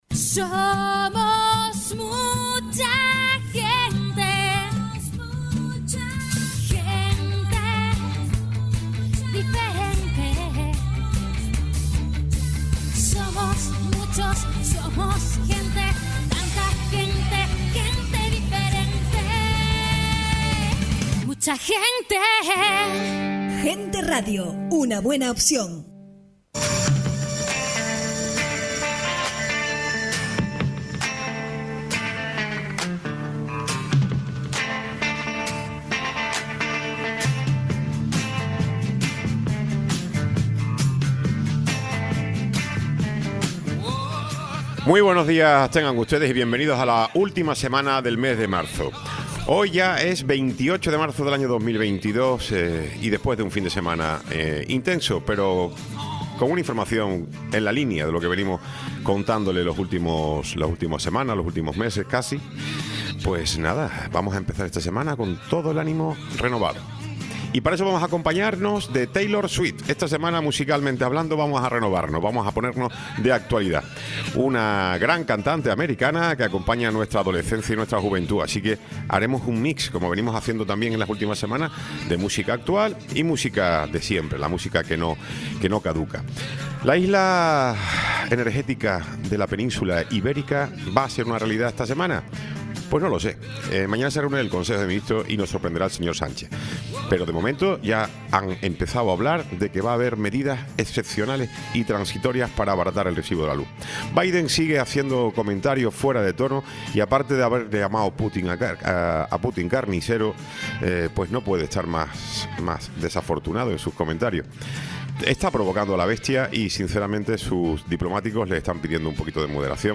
Tiempo de entrevista con Ángel Montañés, concejal PP en el Ayto. de Puerto de la Cruz